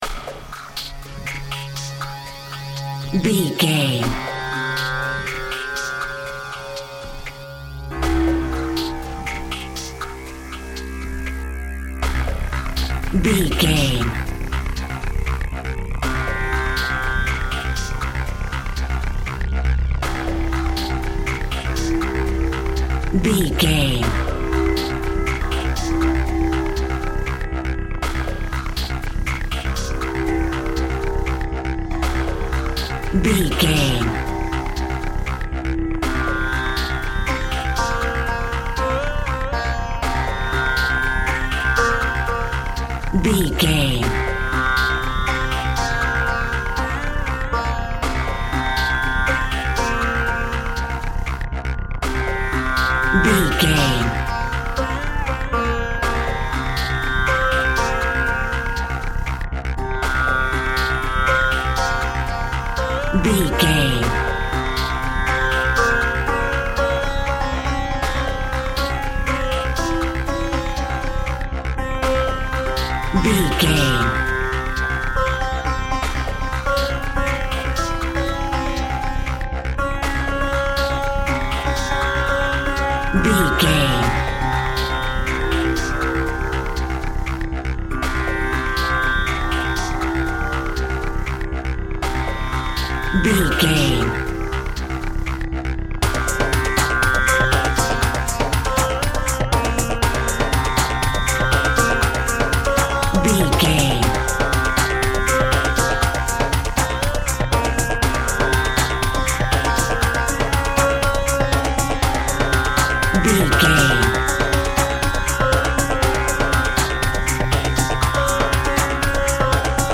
The perfect music for tripping and getting high!
Atonal
D♭
Slow
ambient
electronic
downtempo
pads
strings
dark
drone
glitch
Synth Pads